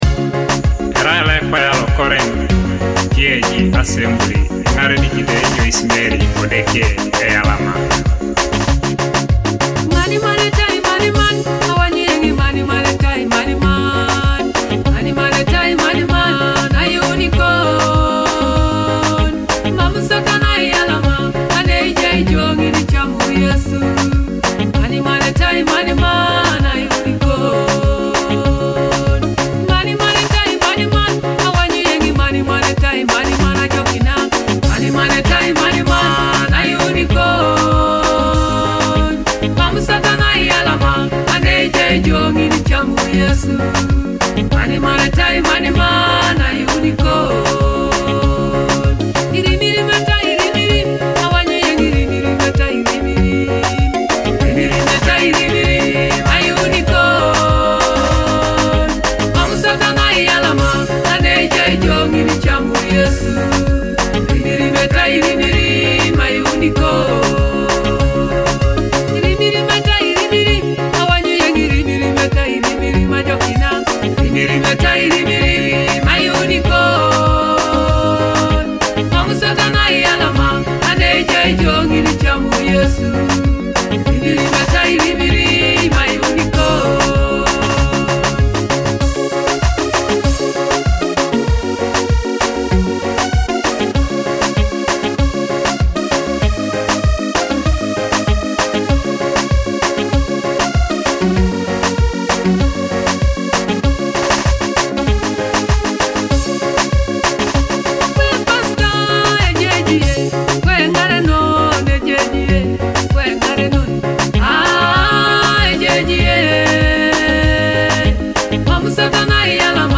A powerful gospel song on salvation and overcoming trials.